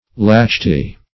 lacerti - definition of lacerti - synonyms, pronunciation, spelling from Free Dictionary
Search Result for " lacerti" : The Collaborative International Dictionary of English v.0.48: Lacertus \La*cer"tus\ (l[.a]*s[~e]r"t[u^]s), n.; pl.